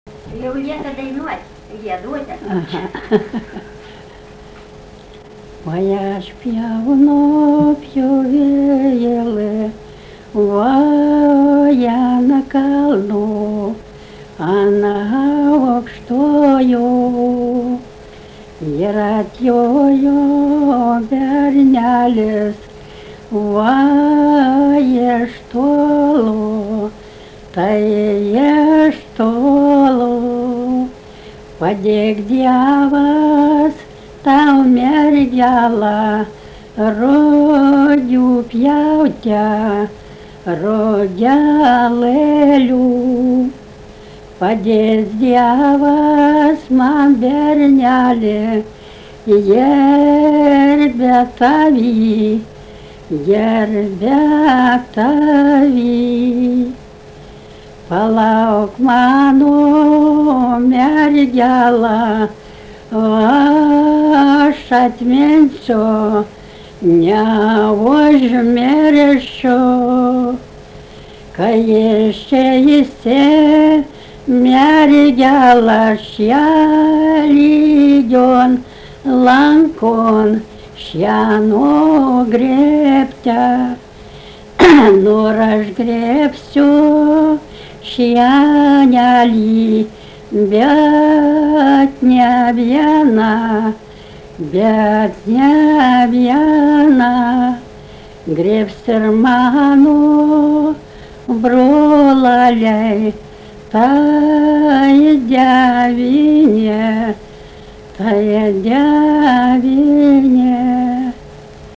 daina, kalendorinių apeigų ir darbo
Druskeliškės
vokalinis